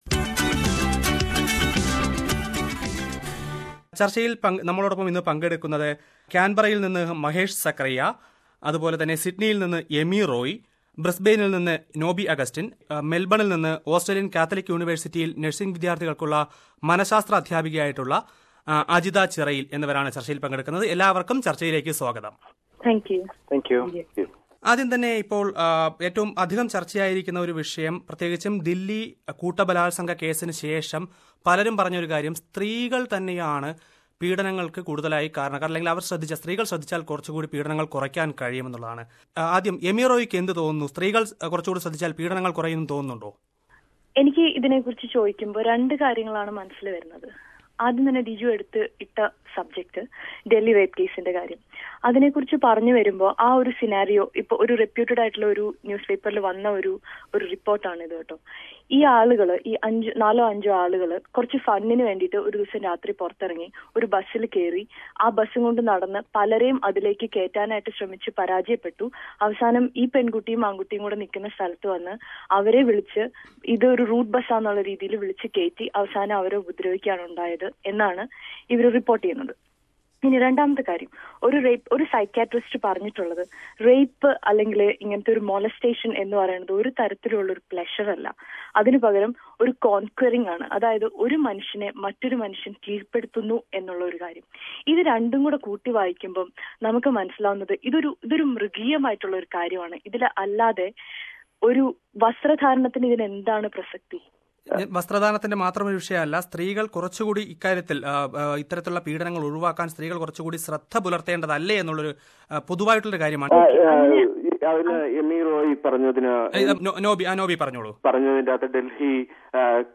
Who is to Blame in Rape Cases: A Panel Discussion
A group of Australian Malayalees discuss the issue on SBS Malayalam Radio